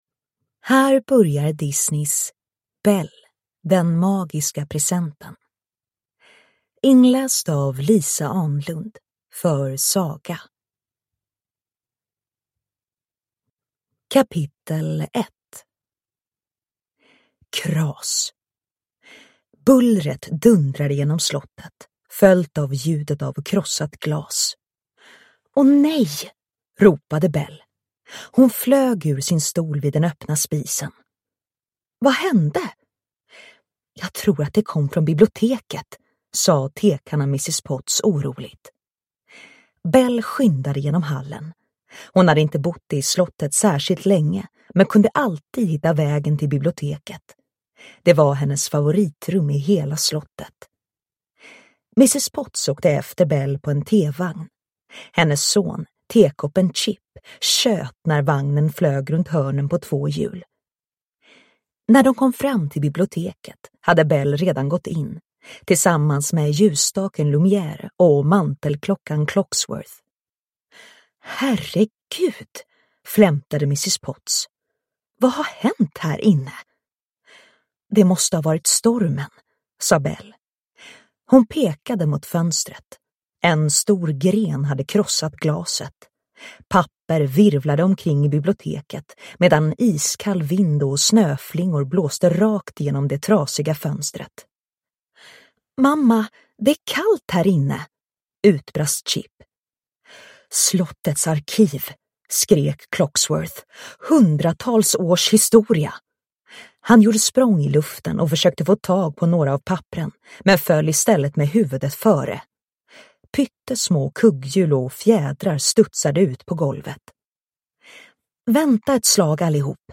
Belle - Den magiska presenten (ljudbok) av Disney